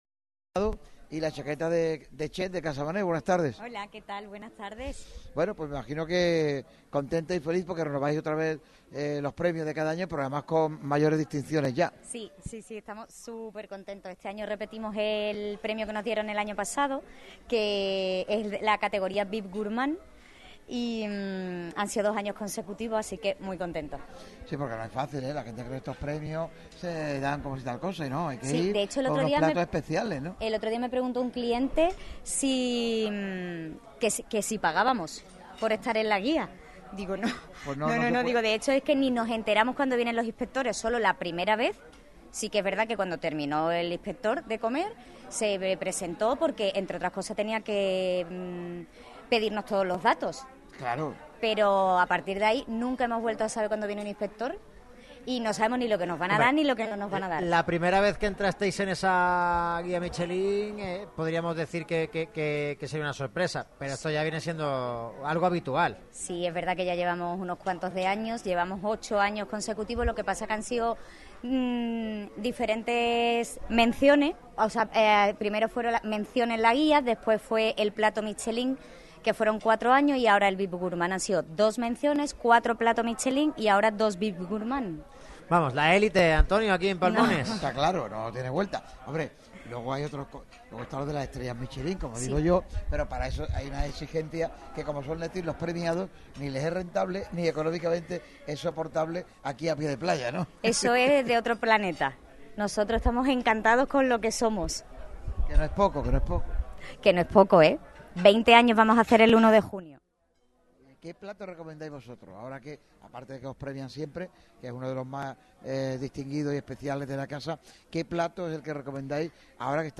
Radio MARCA Málaga celebra el 'Bid Gourmand' 2025 de la Guía Michelín que ha recibido el restaurante Casa Mané situado en pleno corazón de la playa de Palmones en Algeciras con un programa especial este miércoles 26 de marzo